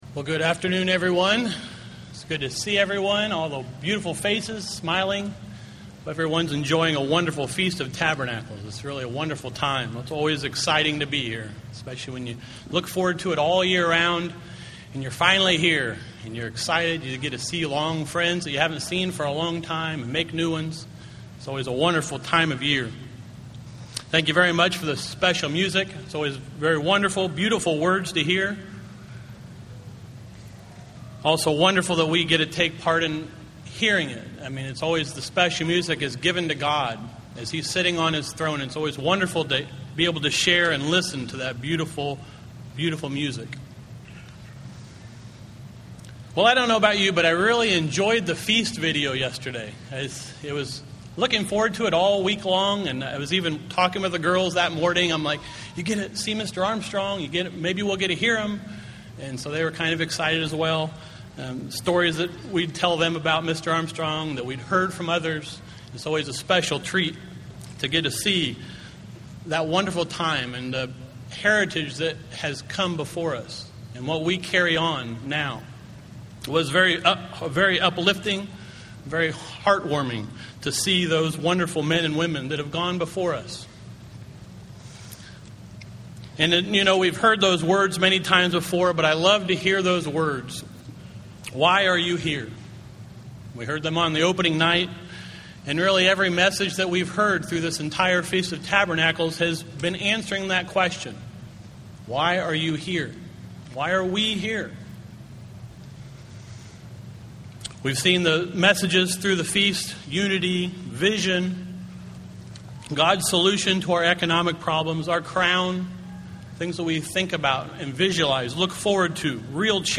This sermon was given at the Wisconsin Dells, Wisconsin 2008 Feast site.